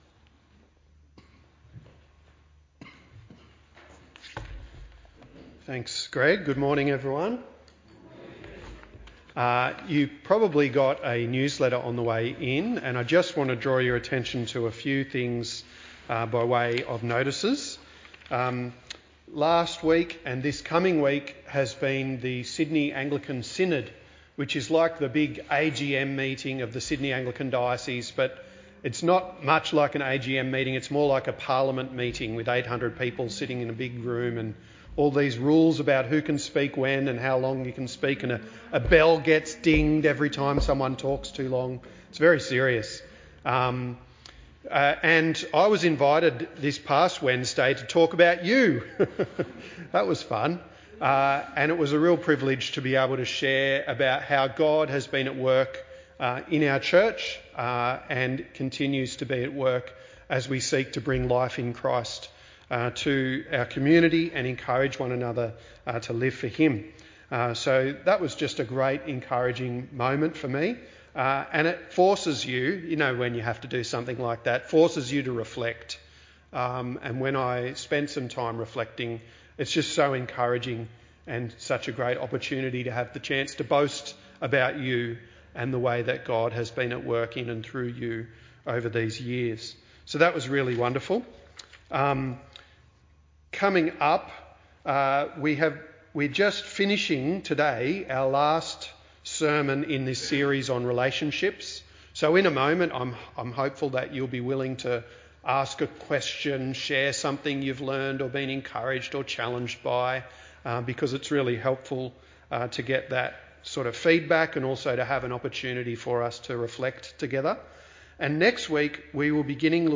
There is a Q&A session at the beginning of this recording. While it is difficult to hear the questions from the congregation, the focus of the question can be guessed from the helpful and thought provoking answers